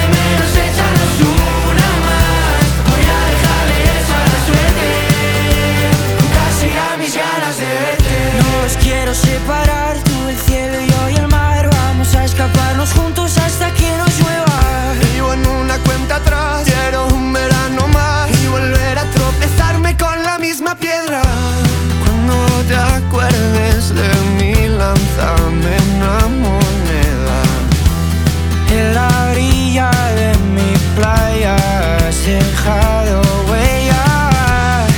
Скачать припев
2025-05-30 Жанр: Поп музыка Длительность